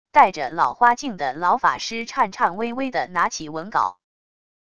带着老花镜的老法师颤颤巍巍的拿起文稿wav音频